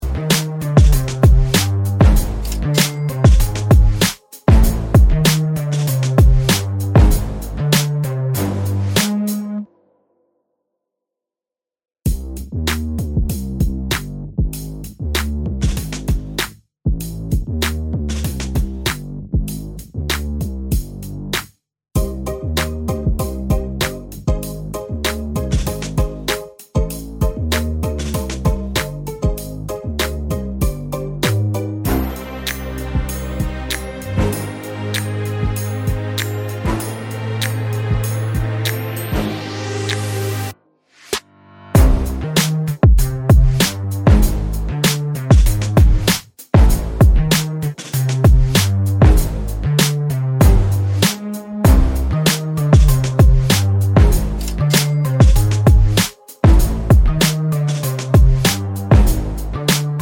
Explicit Backing Vocals Pop (2020s) 2:14 Buy £1.50